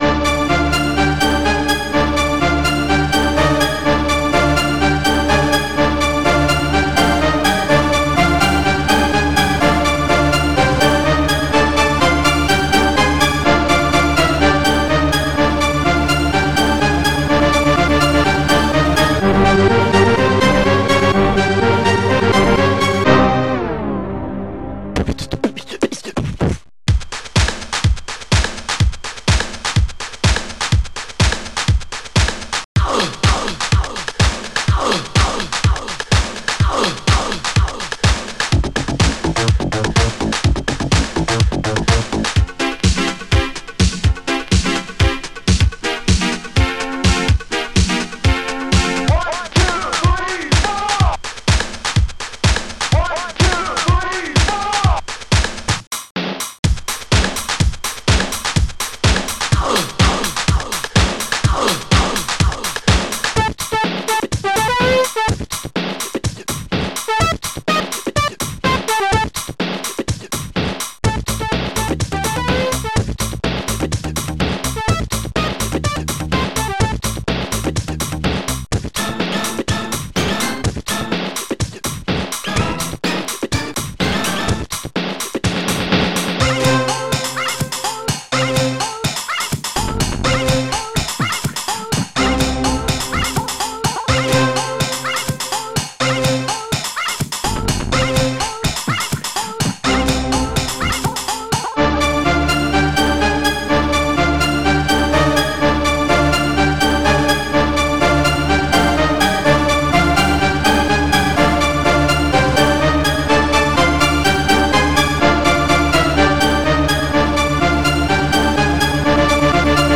Drum_Baass
hardsnare
hihat-4
BRASS3
HORNBLAST3